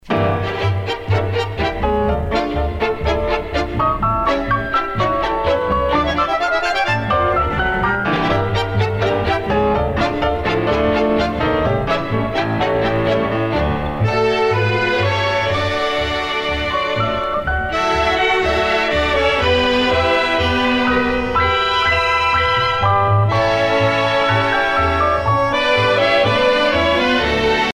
danse : tango